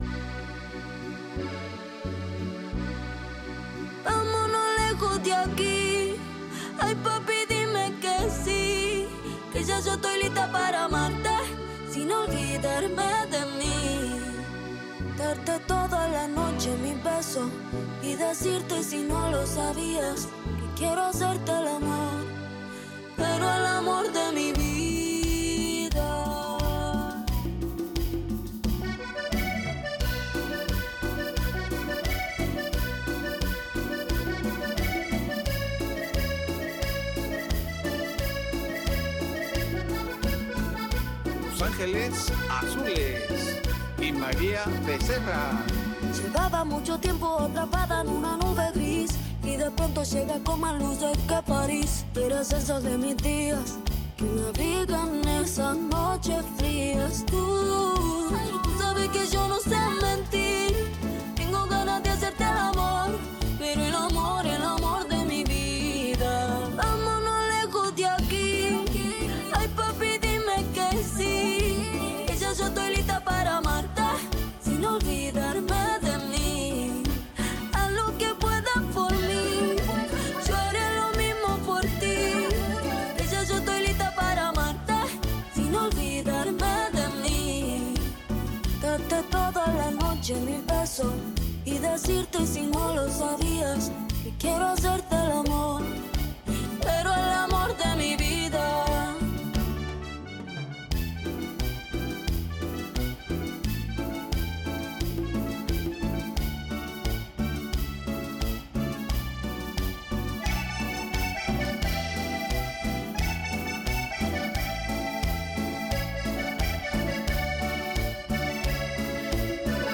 El instrumental está bueno bro.